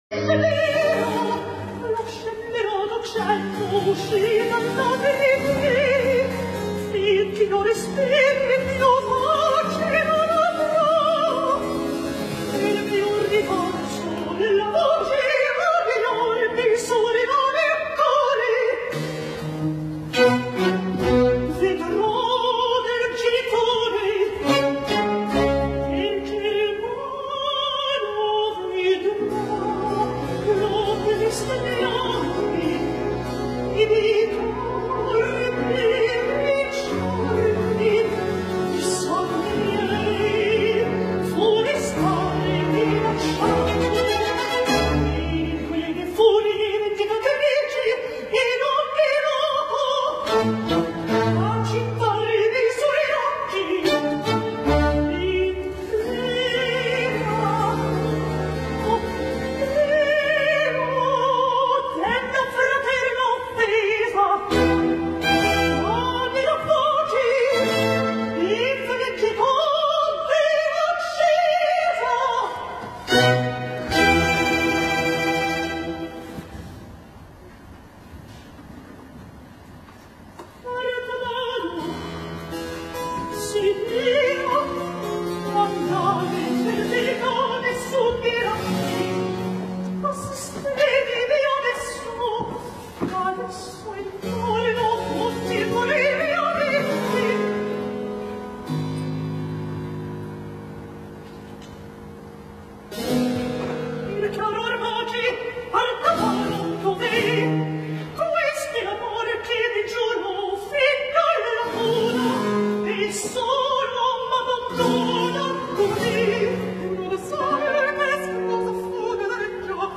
I, 9-10 Recitativo e aria Artaserse.mp3 — Laurea Triennale in Scienze e tecnologie della comunicazione